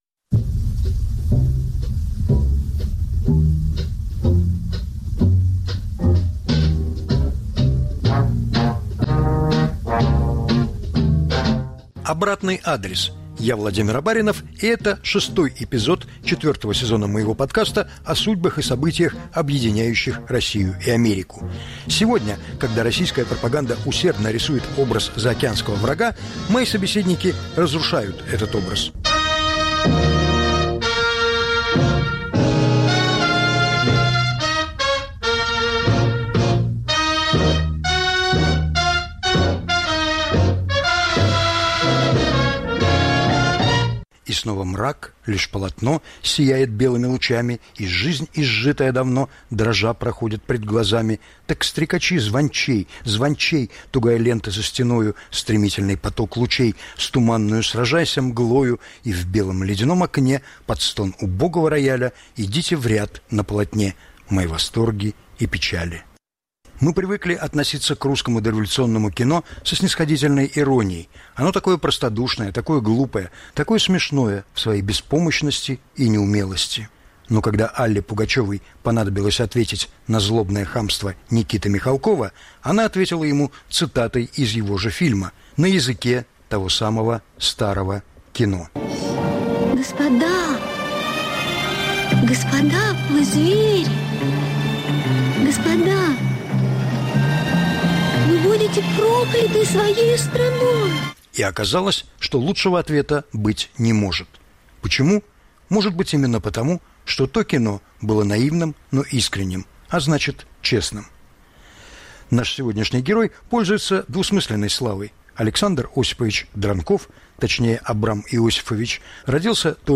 повтор эфира от 21 октября 2022 года.